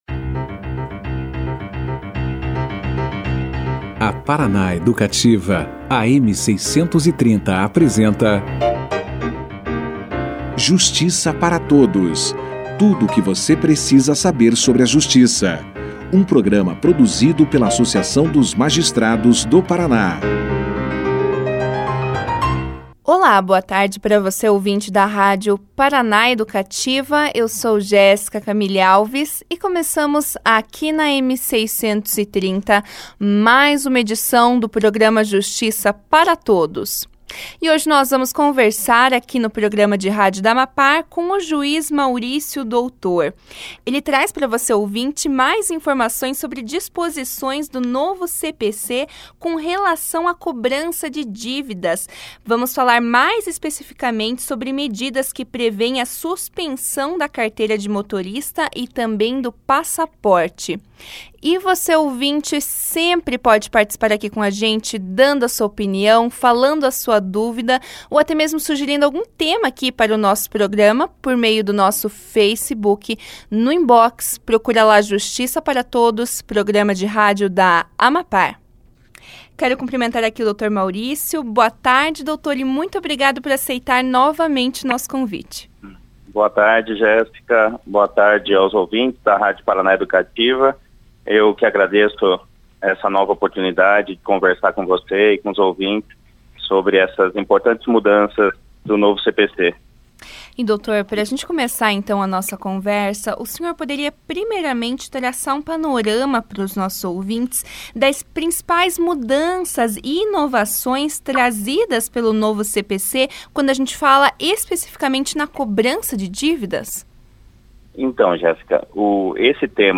As inovações trazidas pelo novo Código de Processo Civil no que concerne a execução foram discutidas na sexta-feira (8), no programa de Rádio da AMAPAR, o Justiça para Todos. O juiz Maurício Doutor, convidado do dia, falou aos ouvintes da Paraná Educativa a respeito da possibilidade, trazida pelo CPC, de suspensão da carteira de motorista e do passaporte do devedor.